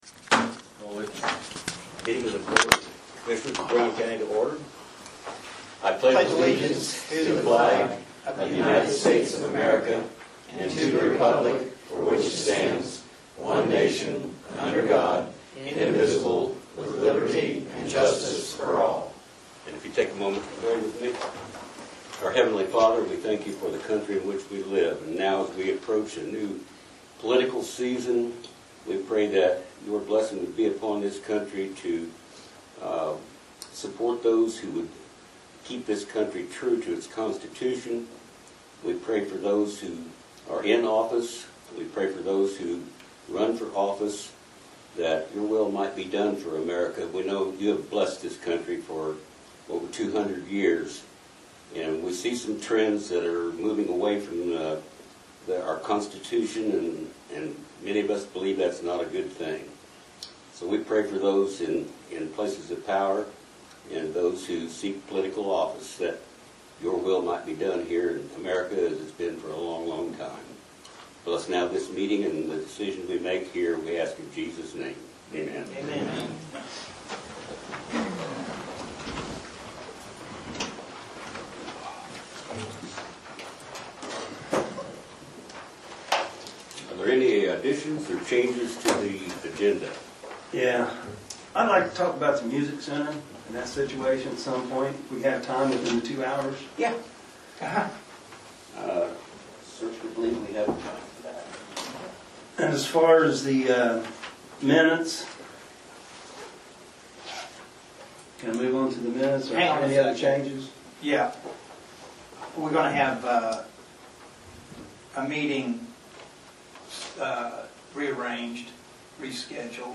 Audio of the Meeting – Length 2 hrs.